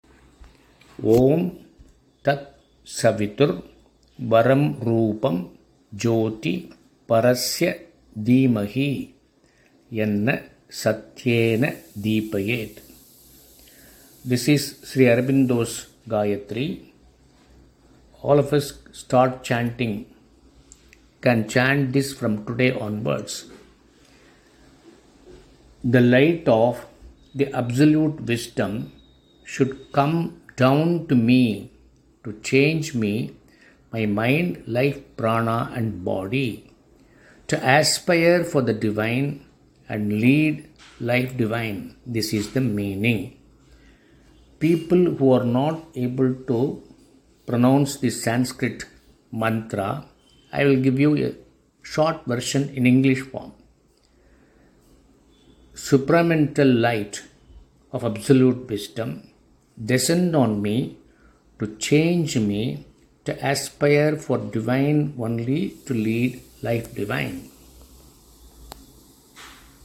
All of us can start chanting from today onwards.